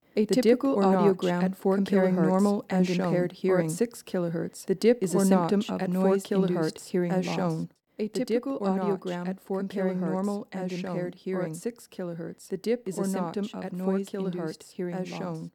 Same voice doubled at same location (mono), and then at two locations (stereo); notice how easy it is to follow even the same voice when spatially separated from another; this reflects the brain's ability to enhance one neural signal and suppress others (assuming you have good hearing ability! those with hearing impairment cannot do this readily)
Cocktail_Party_Effect.aiff